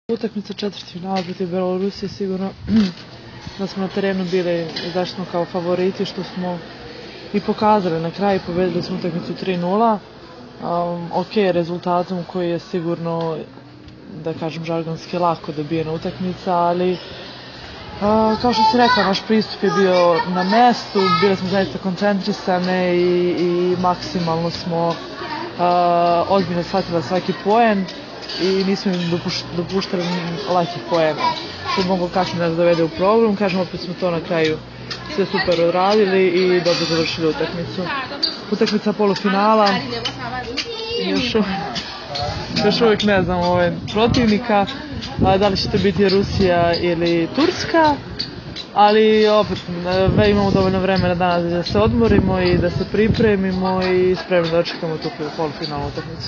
IZJAVA TIJANE MALEŠEVIĆ